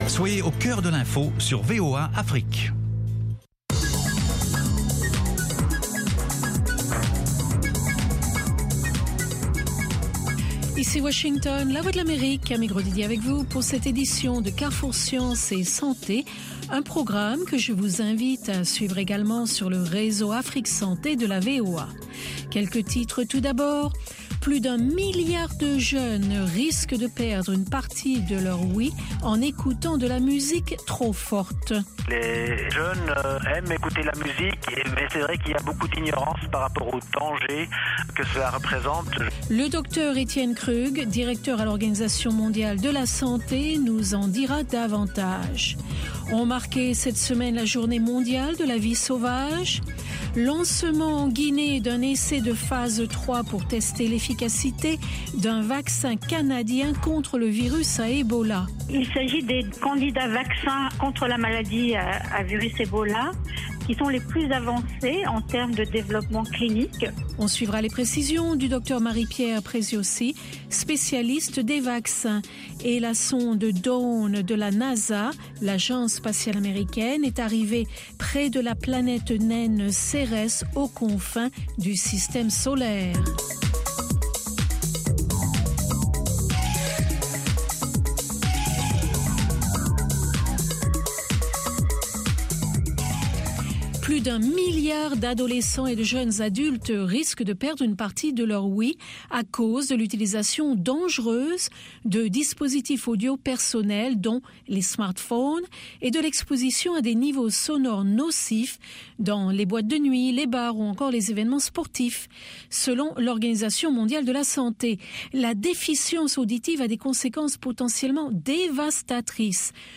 Carrefour Sciences et Santé vous offre sur la VOA les dernières découvertes en matière de technologie et de recherche médicale. Il vous propose aussi des reportages sur le terrain concernant les maladies endémiques du continent africain.